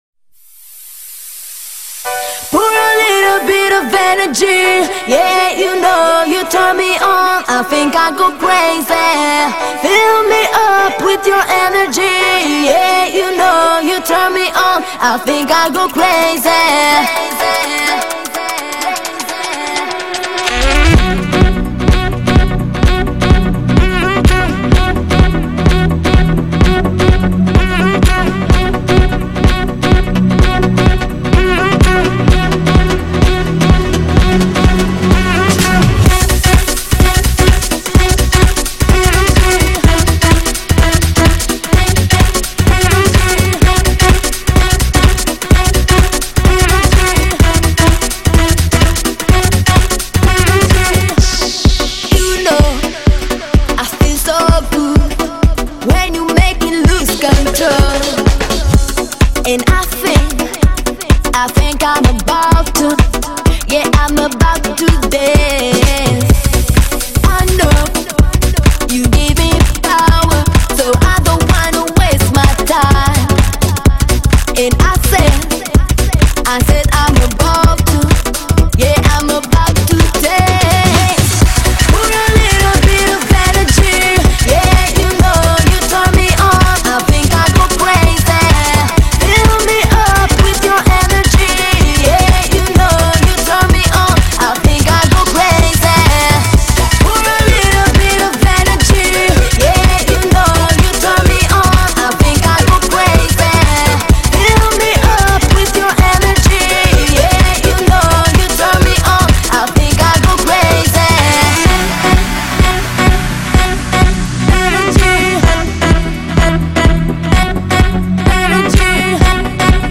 музыка попса